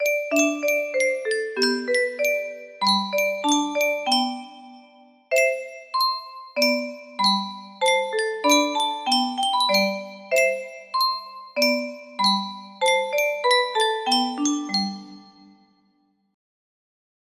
Clone of Yunsheng Spieluhr - Min Vater isch en Appezeller 1282 music box melody